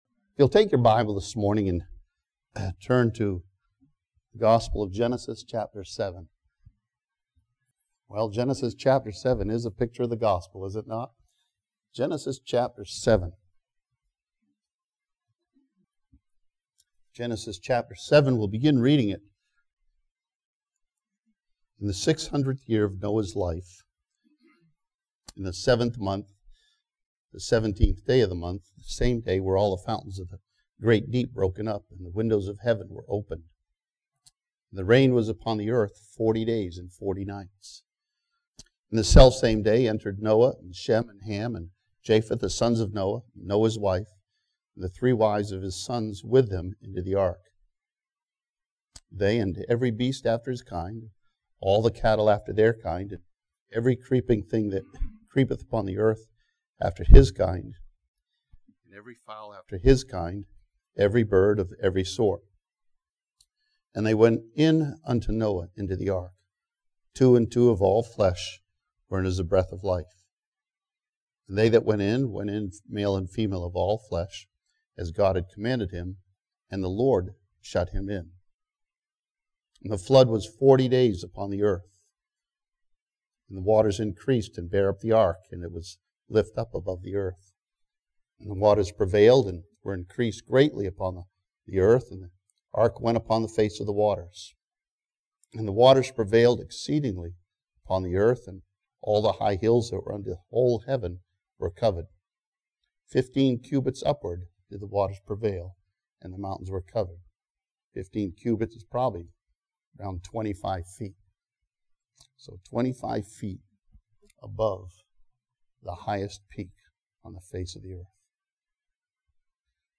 This sermon from Genesis 7 studies Noah and learns that as God remembered Noah, so God remembers you.